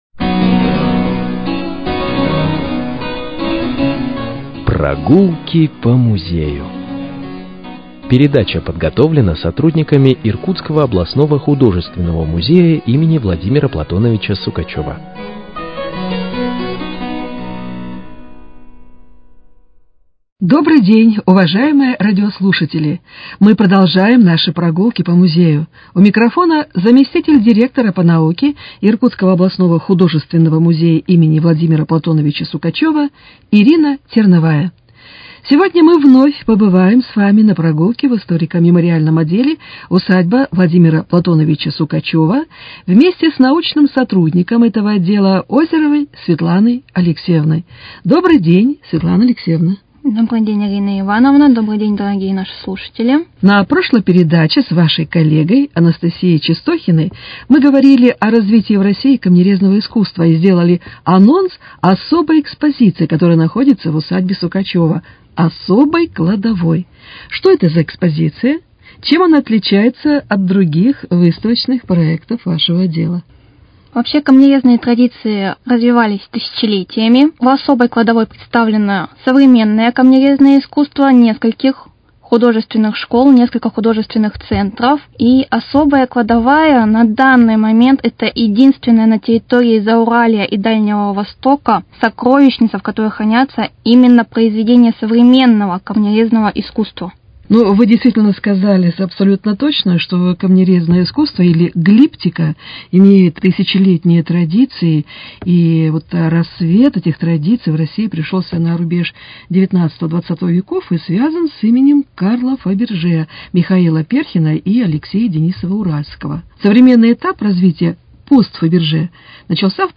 Передача